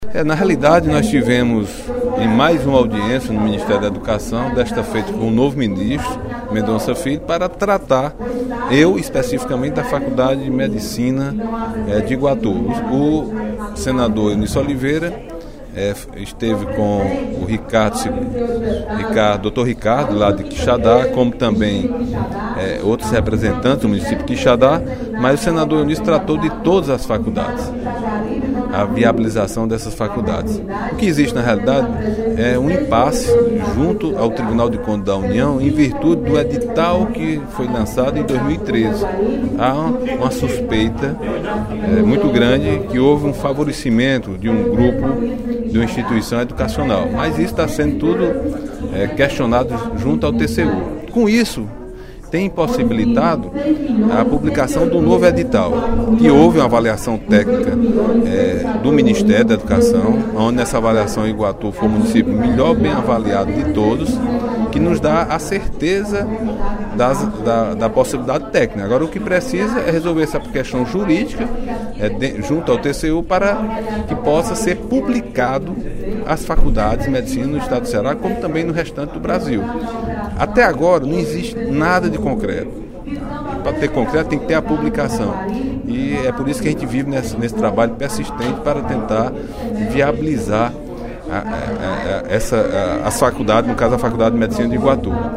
O deputado Agenor Neto (PMDB) ressaltou, durante o primeiro expediente da sessão plenária da Assembleia Legislativa desta quinta-feira (09/06), reunião com o senador Eunício Oliveira (PMDB-CE) e com o ministro da Educação, Mendonça Filho, para tratar da liberação da Faculdade de Medicina no município de Iguatu.